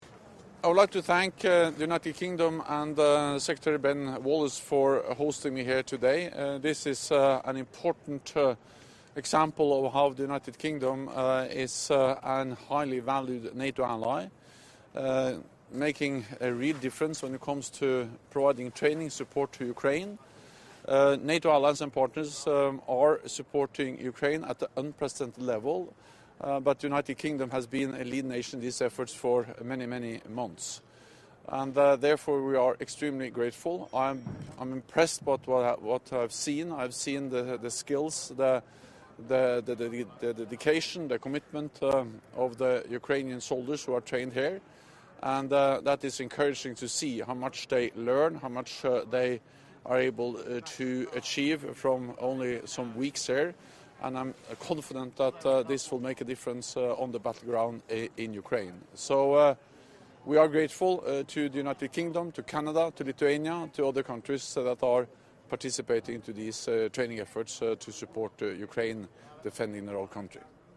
Video NATO Secretary General remarks at Lydd Army Camp 09 Nov. 2022 Audio Remarks by NATO Secretary General Jens Stoltenberg during his visit to the training programme for the Ukrainian Armed Forces at Lydd Army Camp 09 Nov. 2022 | download mp3